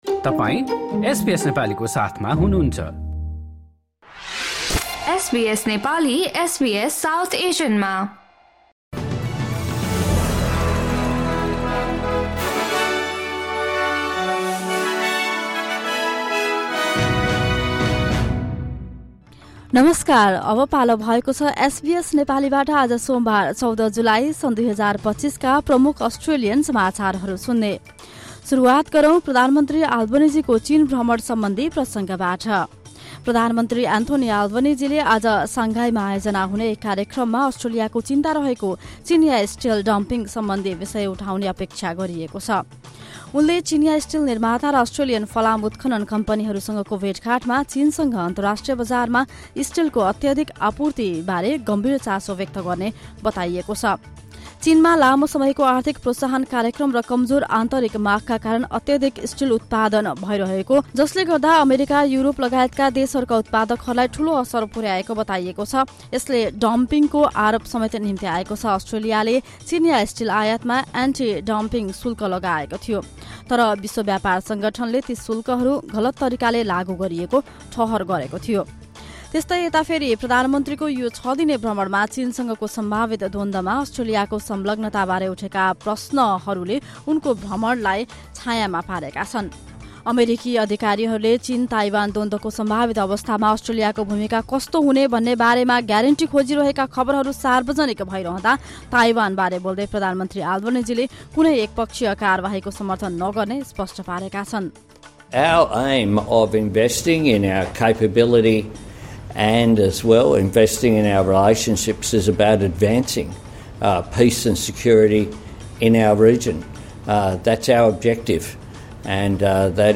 Listen to the latest top news from Australia in Nepali.